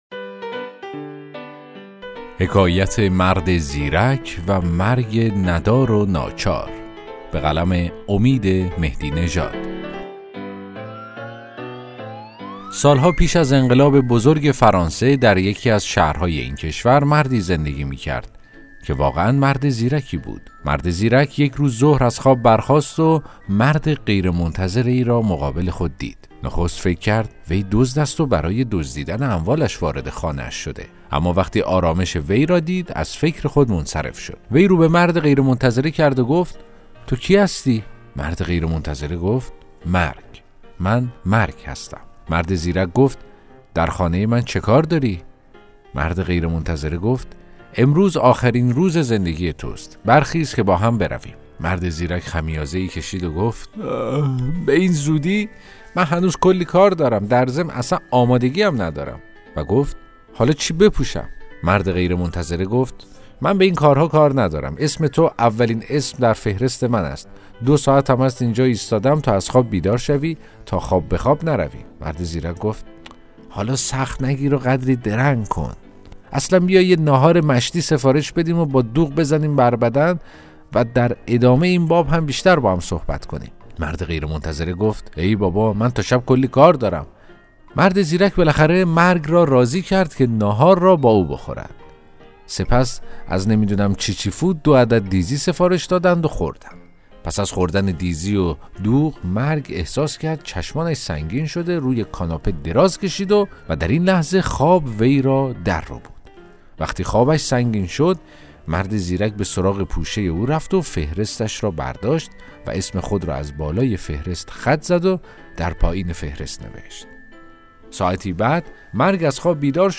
داستان صوتی: مرد زیرک و مرگ ندار و ناچار